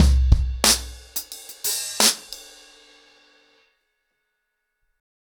ROOTS-90BPM.43.wav